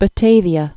(bə-tāvē-ə)